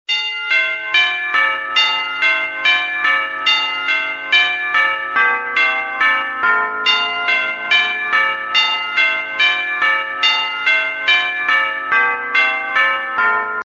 Рингтон Колокольный звон